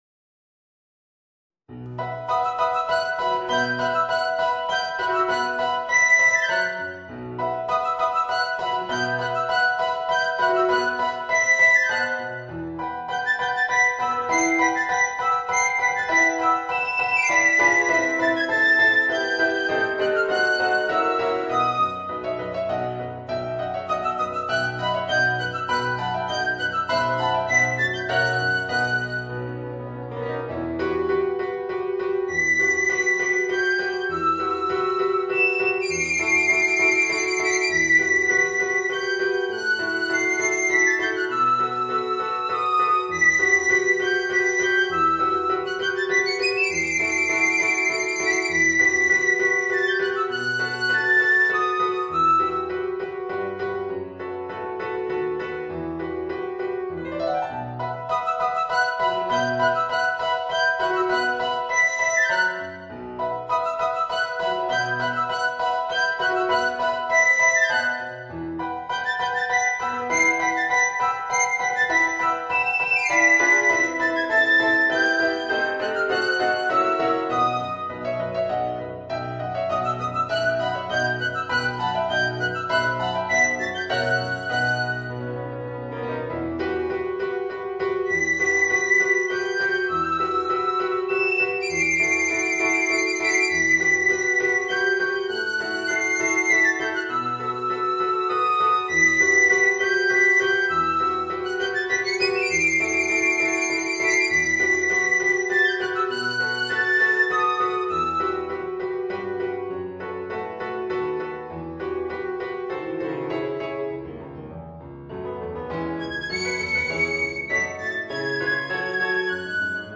Voicing: Piccolo and Piano